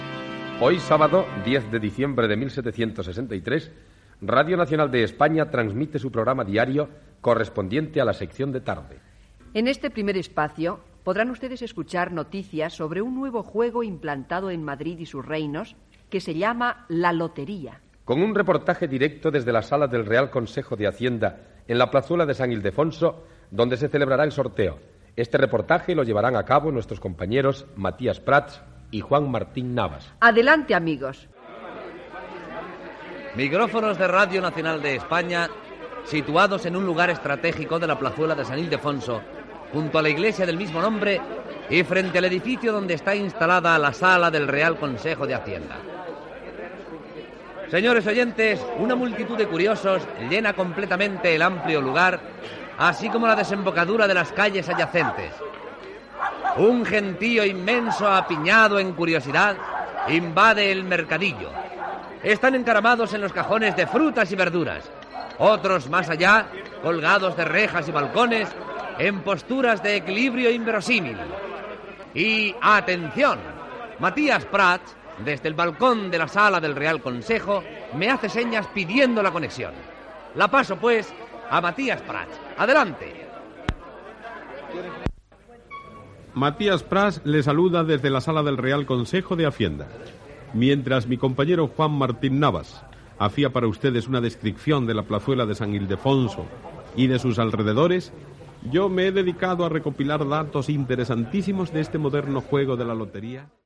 Reporatatge des de la placeta de Sant Hildefonso de Madrid i des de la sala del Real Consejo de Hacienda on es farà el primer sorteig de loteria el 10 de desembre de 1763
Ficció